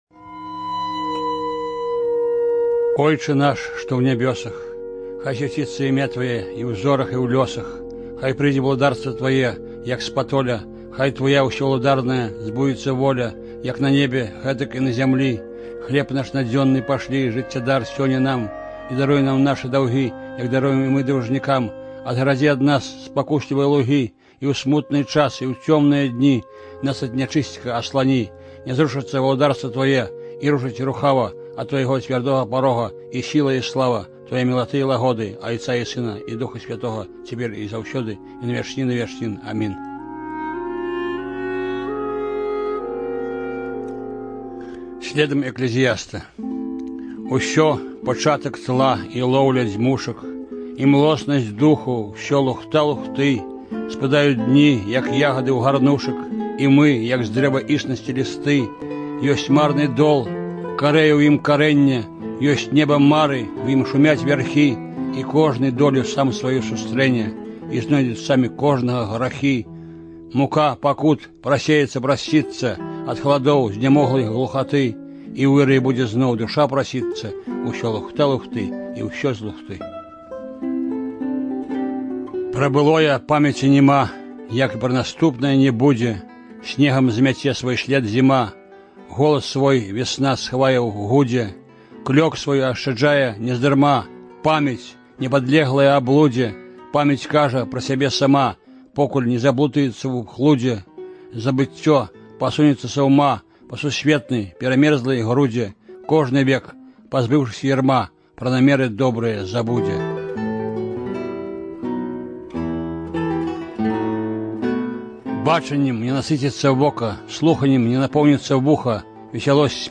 ЧитаетАвтор
ЖанрПоэзия, Книги на языках народов Мира